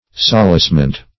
Solacement \Sol"ace*ment\, n.